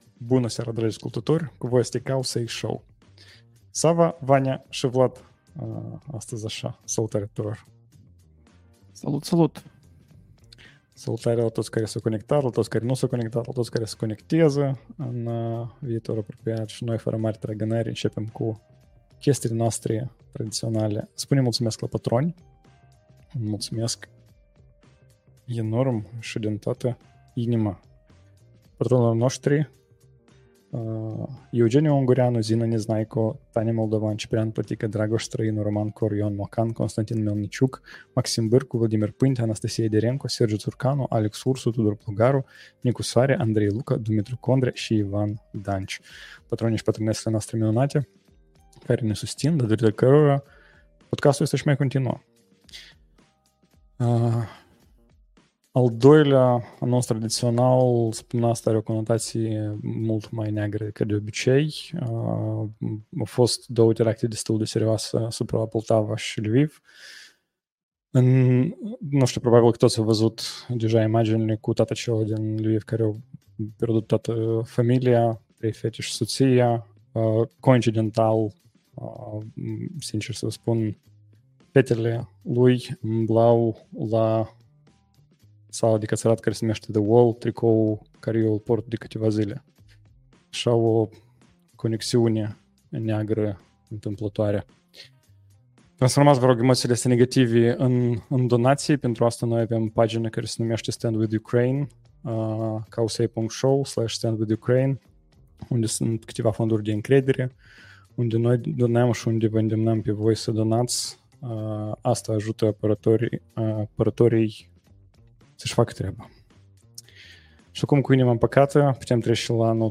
#119: Brazilia blochează Twitter, AGE cu STISC se întrec la semnături, AI implantează memorii September 5th, 2024 Live-ul săptămânal Cowsay Show.